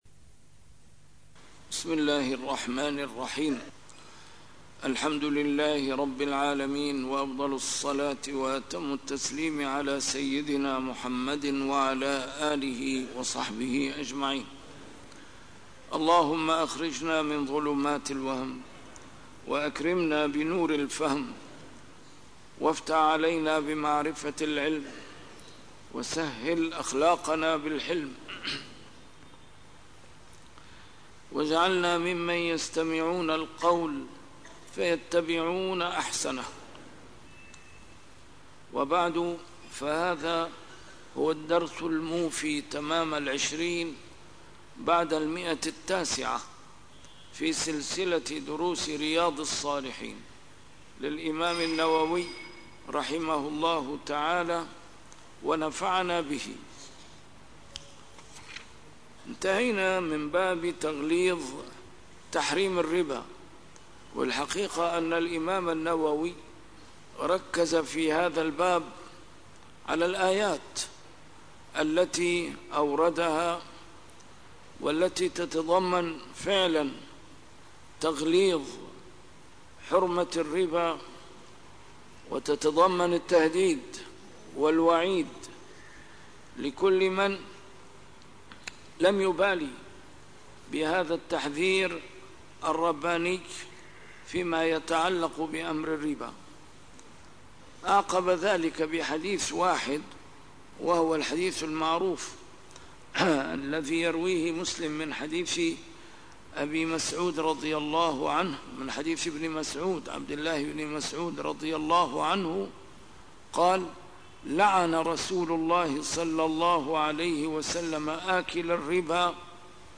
A MARTYR SCHOLAR: IMAM MUHAMMAD SAEED RAMADAN AL-BOUTI - الدروس العلمية - شرح كتاب رياض الصالحين - 920- شرح رياض الصالحين: تغليظ تحريم الربا - تحريم الرياء